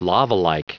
Prononciation du mot lavalike en anglais (fichier audio)
Prononciation du mot : lavalike